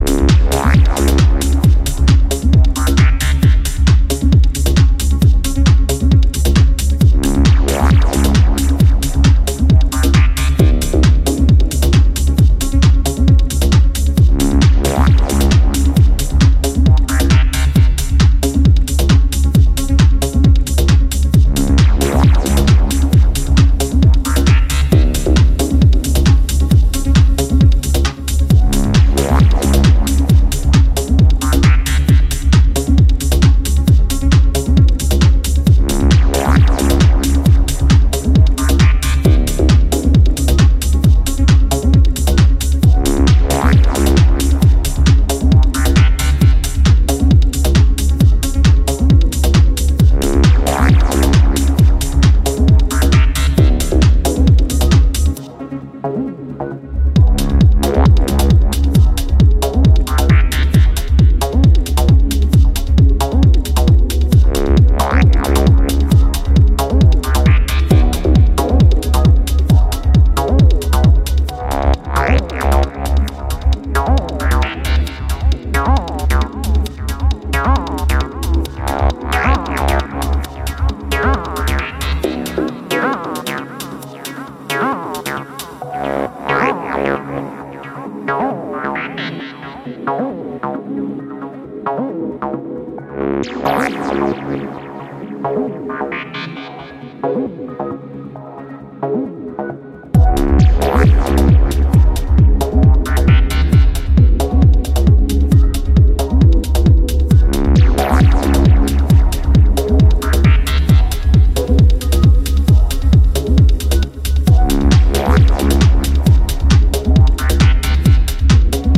ディープにハメてくる感覚が以前よりも研ぎ澄まされてきてると感じさせます。